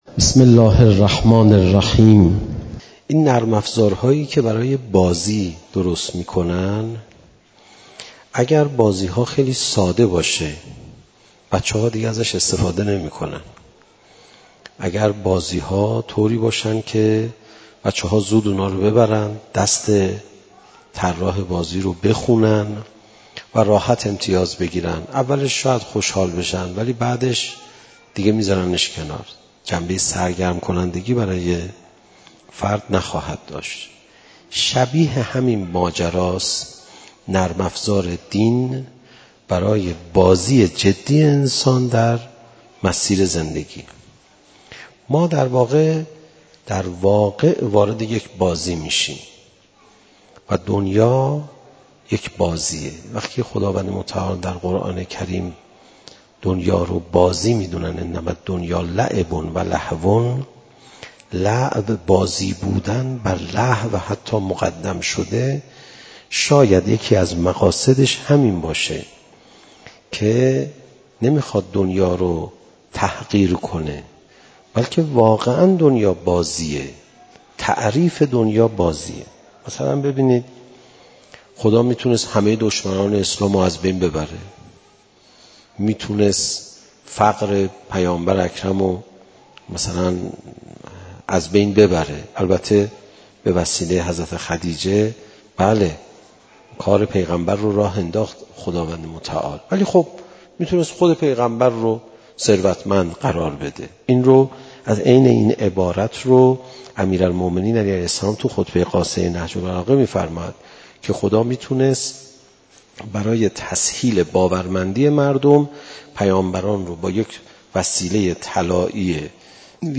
زمان: 01:01:18 | حجم: 14.3 MB | مکان: آستان مقدس حضرت صالح (علیه السلام) - تهران | تاریخ: رمضان 1401ش
قاری : حجت الاسلام عليرضا پناهیان